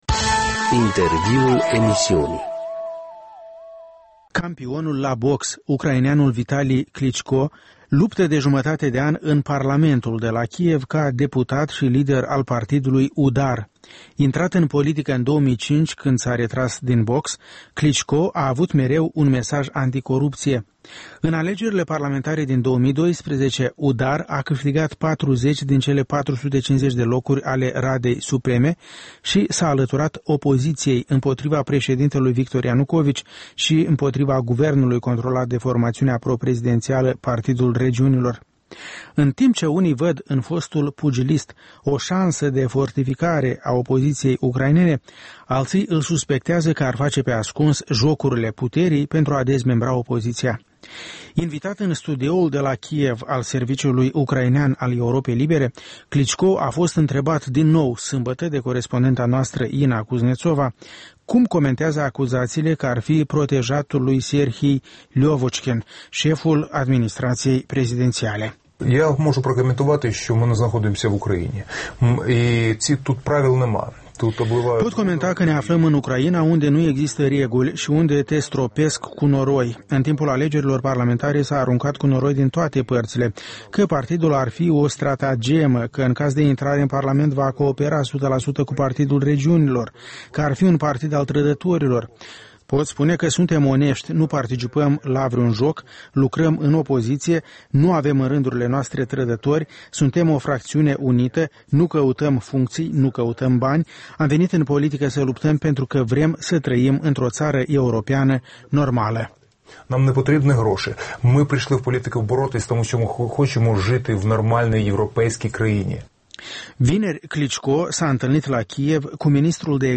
Un interviu despre situația politică din Ucraina cu liderul partidului UDAR, Vitali Kliciko